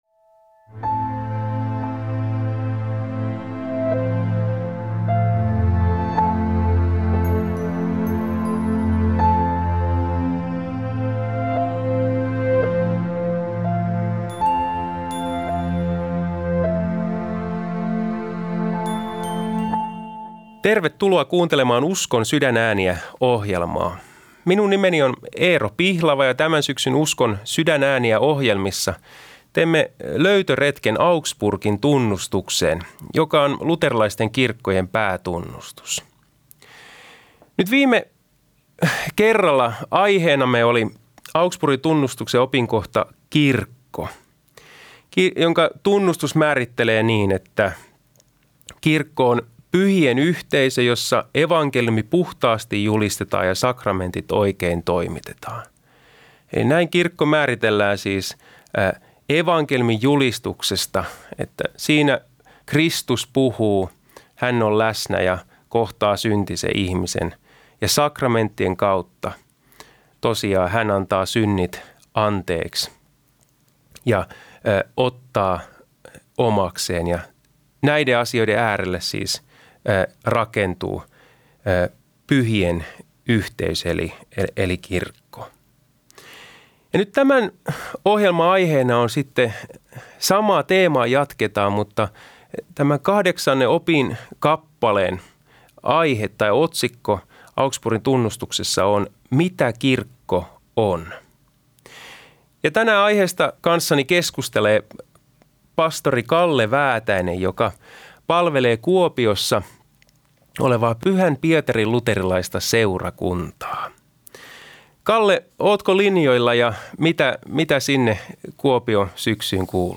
Hän keskustelee opinkappaleista ja niihin liittyvistä aiheista studiovieraiden kanssa.